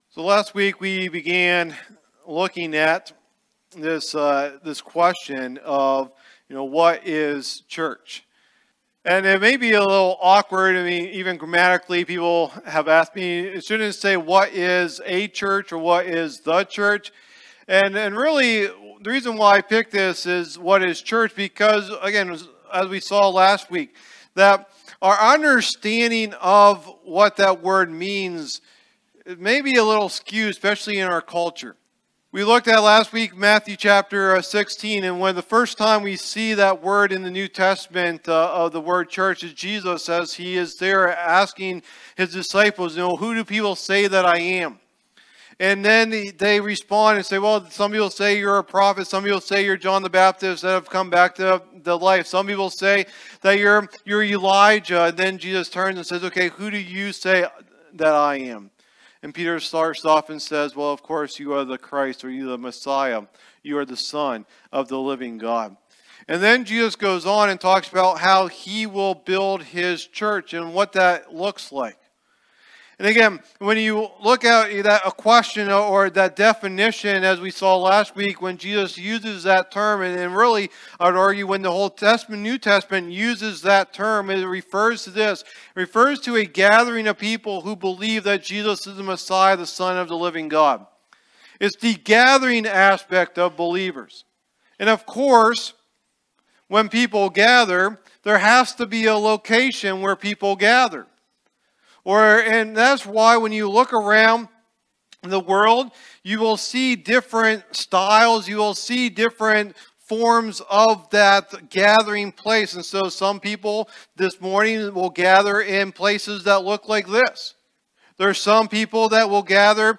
Message #2 in the "What is Church?" teaching series